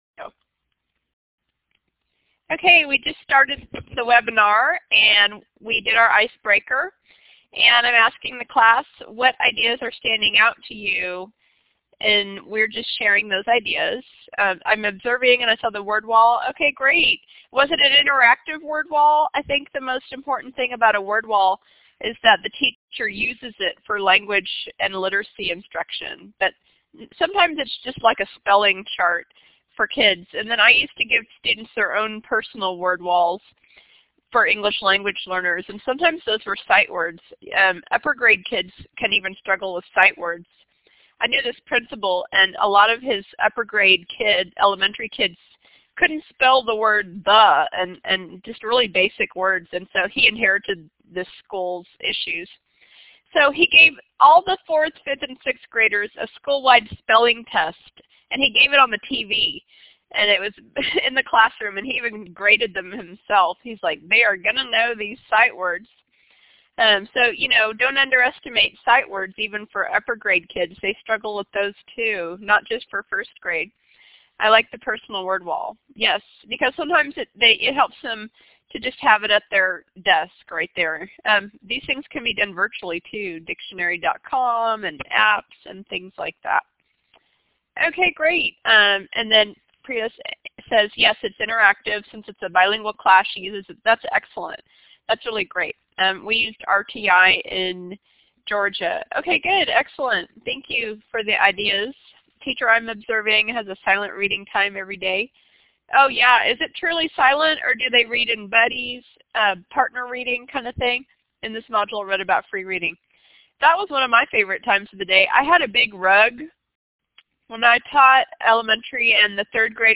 This is the audio version of the webinar for LIST 5361, recorded on 3/22/17.